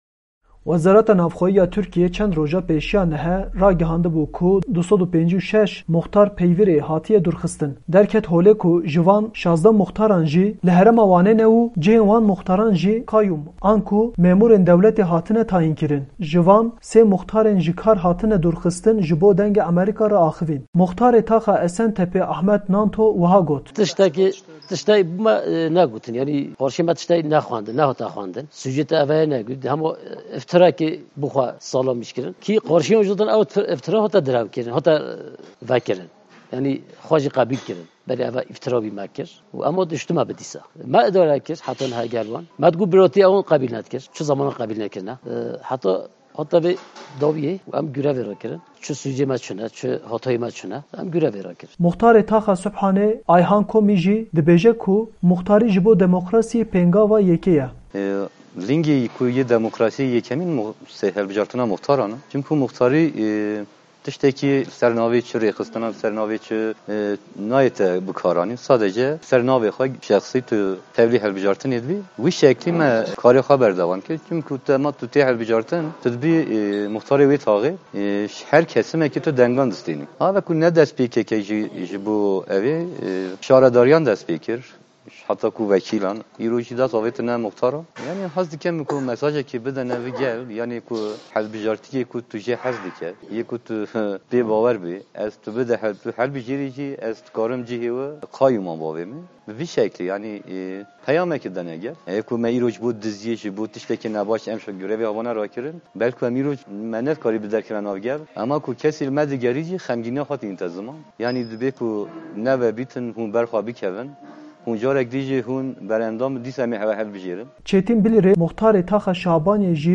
3 muxtarên ku ji kar hatine derxistin, ji Dengê Amerîka re axifîn û nêrînên xwe parve kirin.